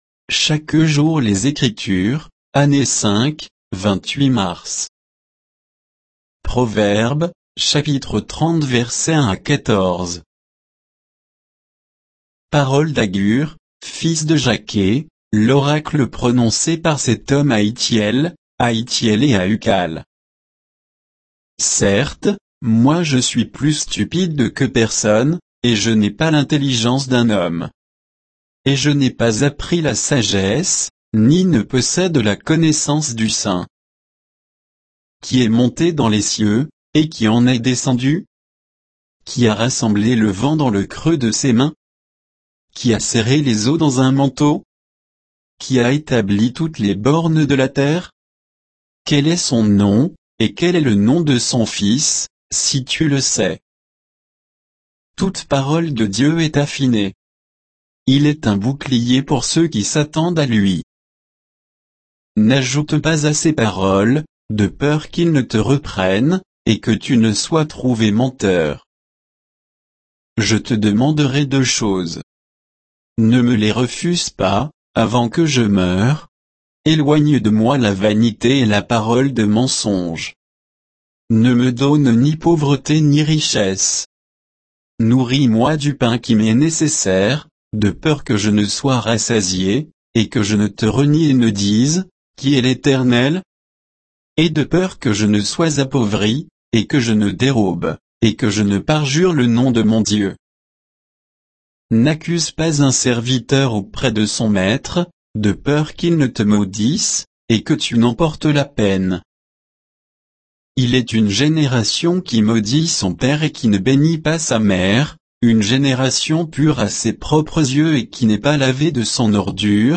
Méditation quoditienne de Chaque jour les Écritures sur Proverbes 30, 1 à 14